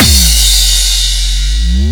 125 BPM Beat Loops Download